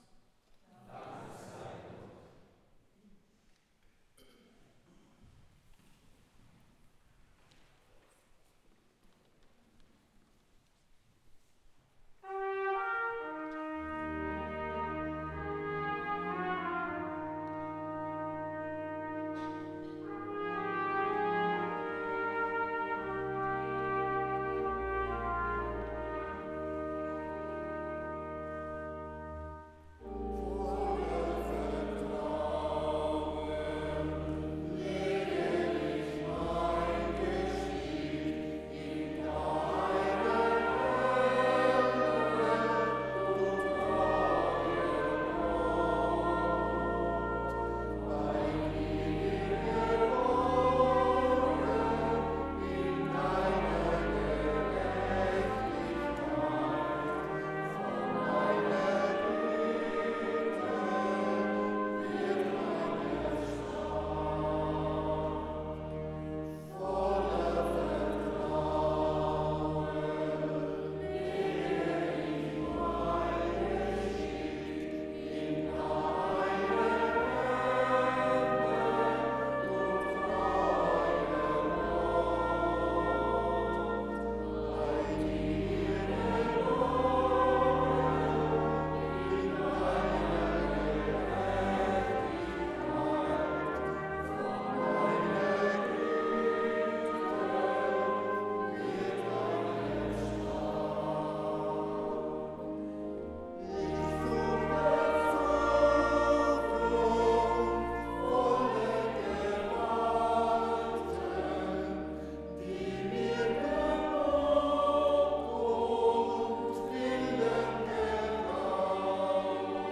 Am 26. Dezember 2025 besuchte unser Bischof, Joseph Maria Bonnemain, unsere Gemeinde St. Stephan. Für einen Einblick in den schönen Gottesdienst klicken Sie unten auf den Button.